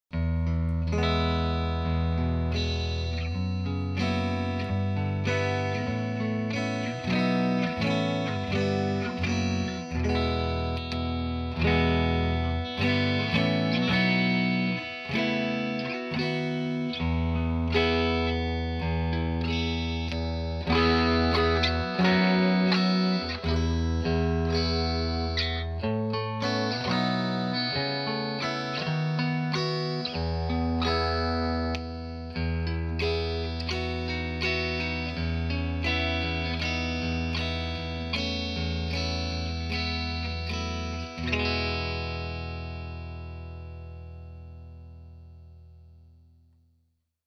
guitartones.wma